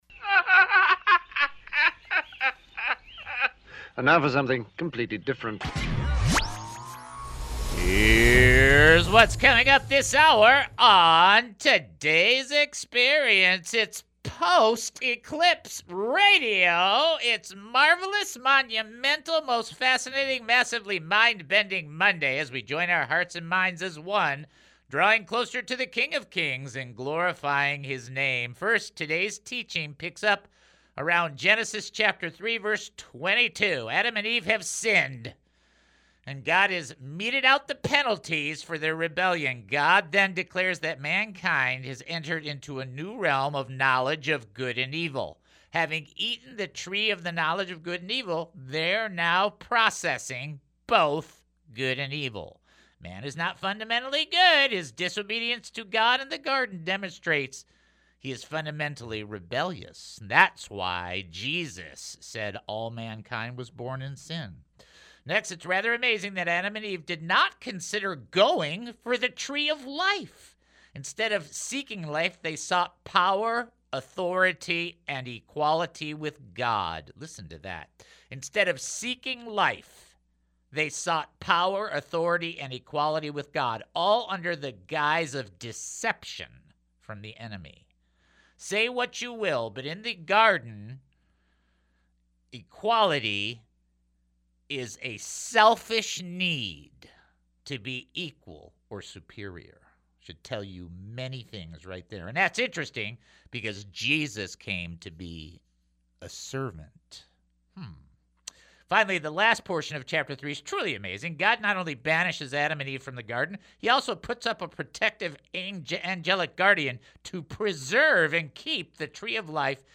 Let’s delve into the fascinating world of The Eclipse Show : The Eclipse Show (A) : This captivating show is a delightful blend of phone calls , fun trivia , and prayer .